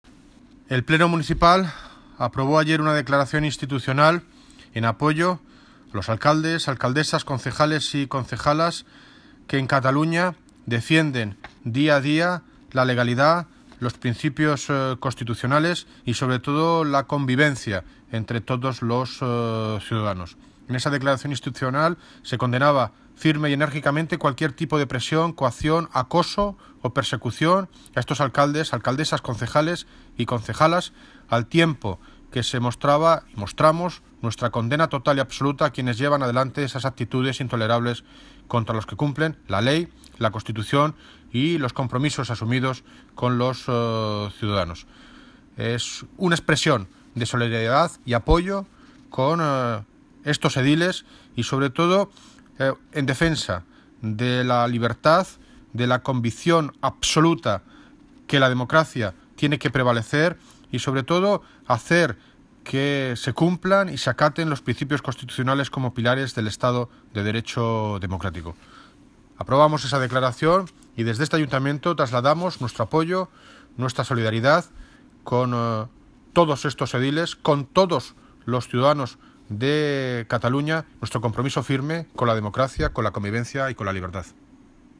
Audio - David Lucas (Alcalde de Móstoles) Sobre CATALUÑA